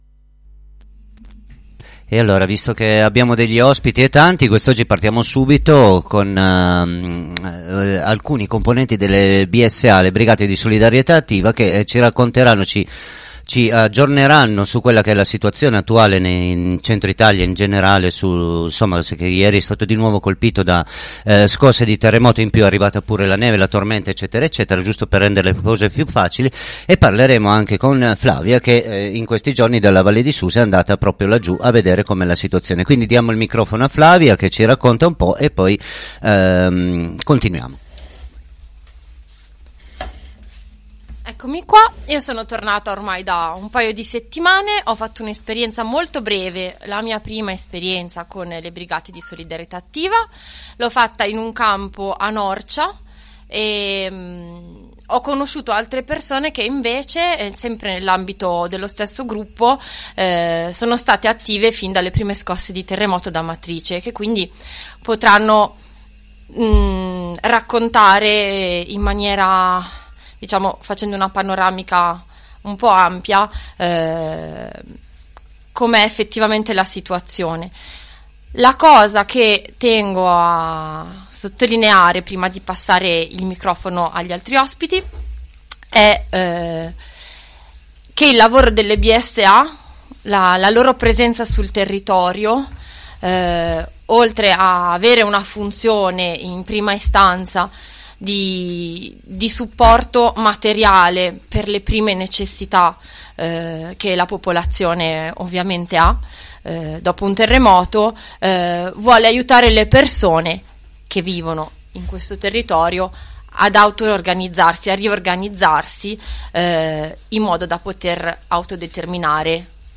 Voci dal corteo#4: Brigate di solidarietà attiva